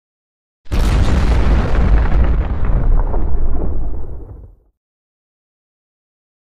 Explosion Low Fire Destruction Version 2 - Light Explosion